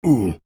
Male_Grunt_Hit_02.wav